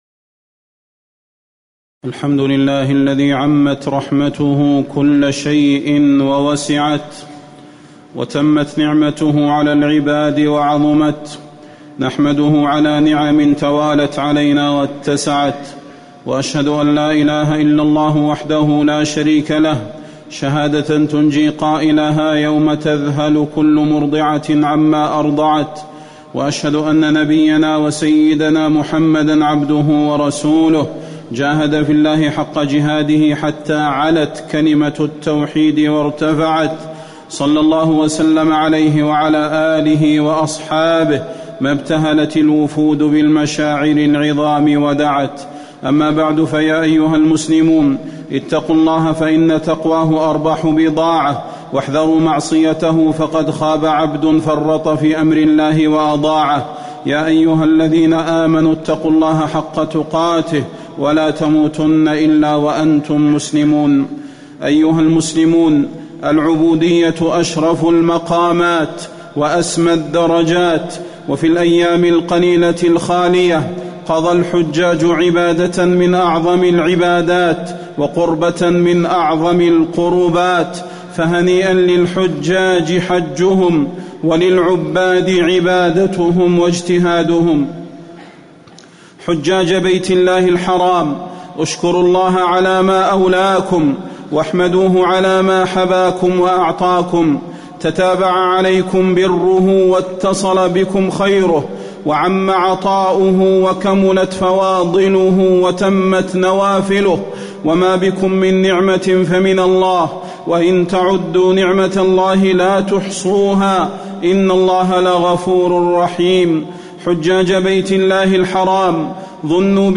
تاريخ النشر ٢٤ ذو الحجة ١٤٣٨ هـ المكان: المسجد النبوي الشيخ: فضيلة الشيخ د. صلاح بن محمد البدير فضيلة الشيخ د. صلاح بن محمد البدير المداومة على الطاعة بعد الحج